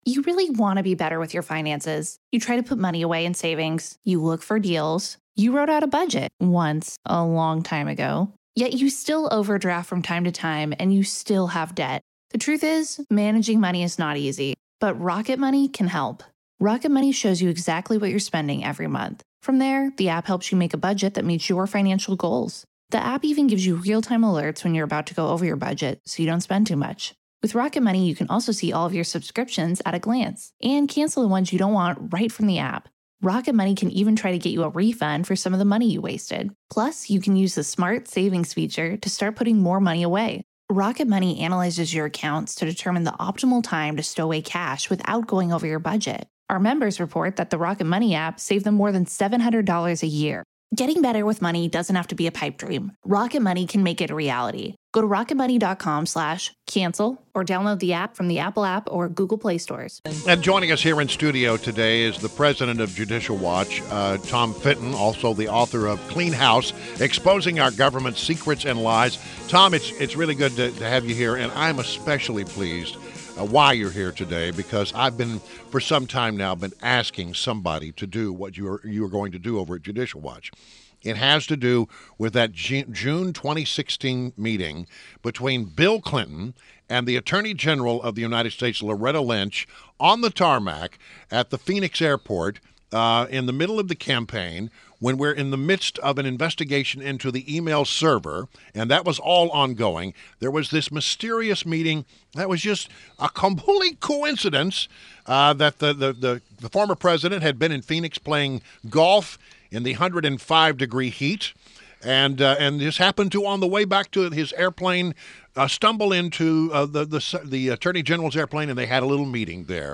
WMAL Interview - TOM FITTON - 03.16.17